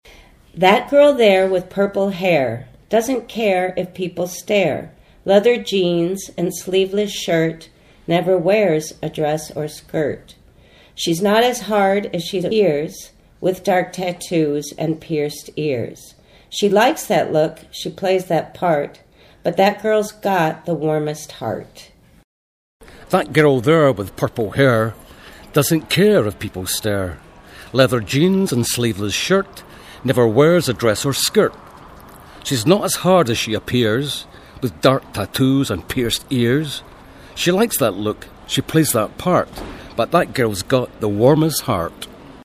American and Scottish R.MP3